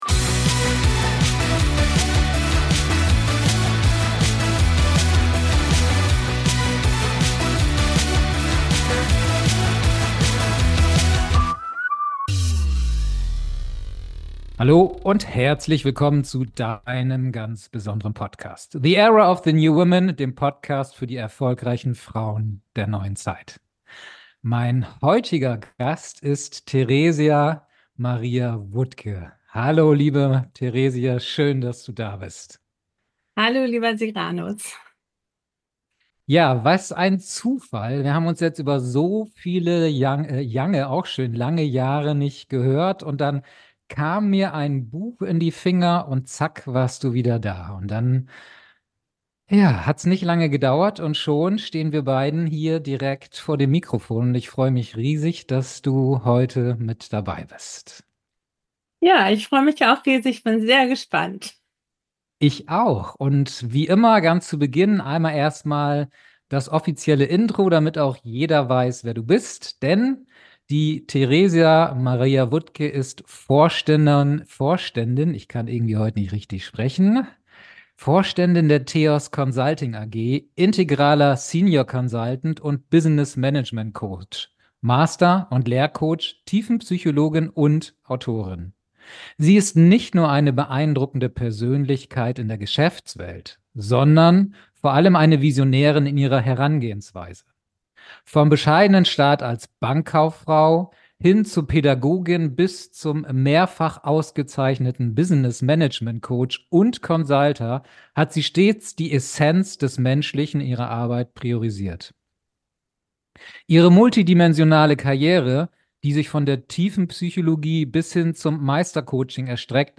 #043 Die Grandezza zukunftsweisender Wertschöpfung. Das Interview